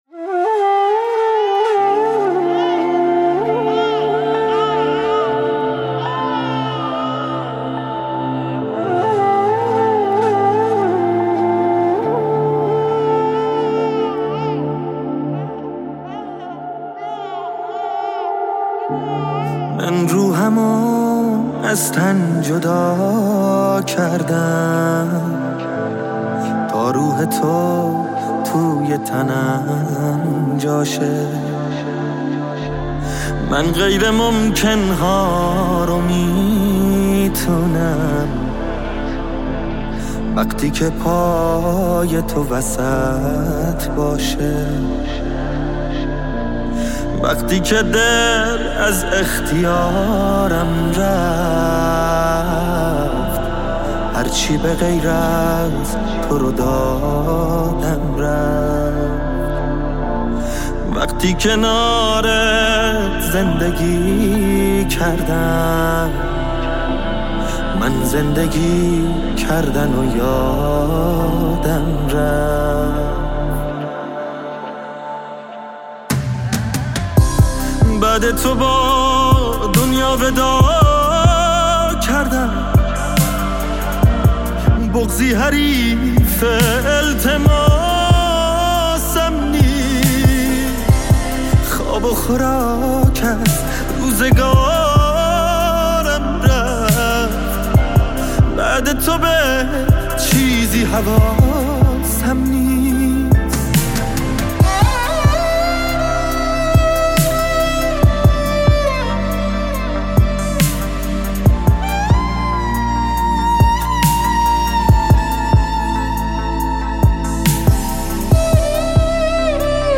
ترانه های سوزناک
خواننده موسیقی پاپ